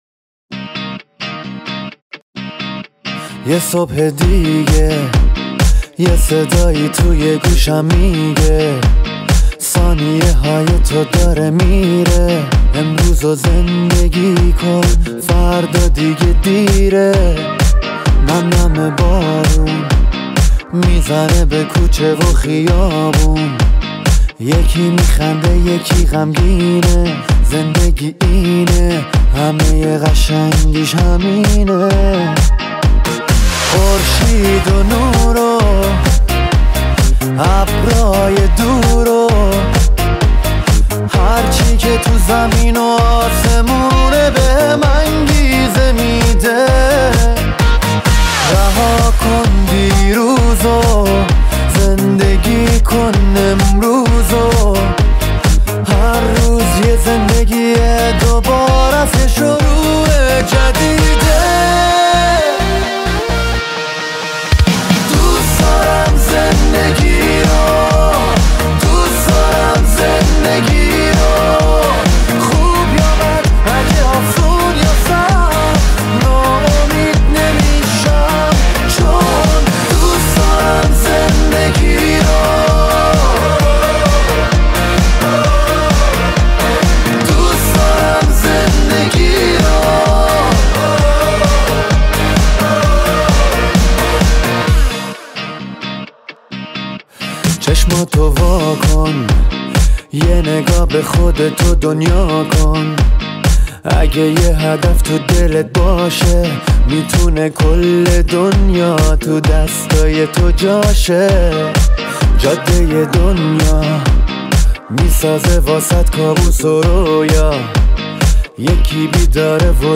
شاد
شاد فارسی